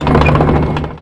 tank-engine-load-rotation-3.ogg